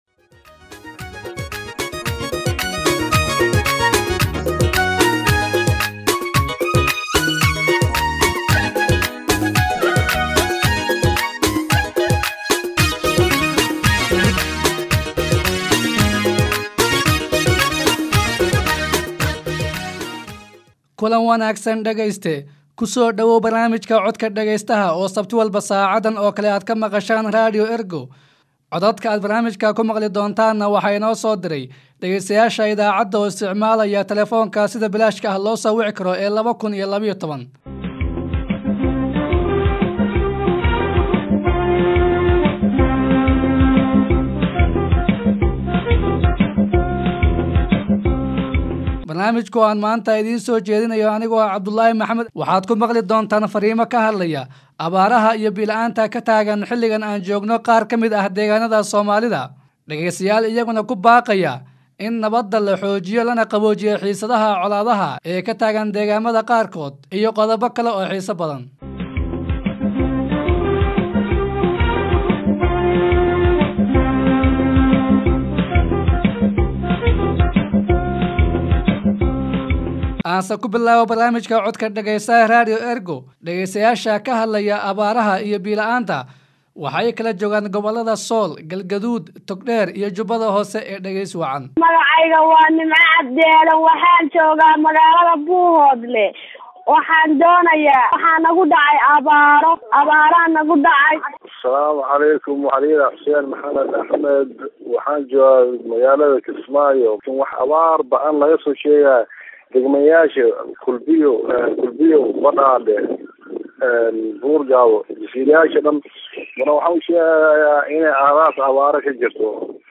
Fikradaha dadka dhegeystayaasha ah ee jooga gudaha Soomaaliya ay kaga soo dhiibtaan barnaamijyada idaacadda Ergo, kuna soo diraan lambarka gaaban ee 2012.
Haddii aad joogto Soomaaliya oo aad jeceshahay in aad barnaamijka ka qeyb qaadato si bilaash ah ayaad farriintaada oo cod ah noogu soo duubi kartaa.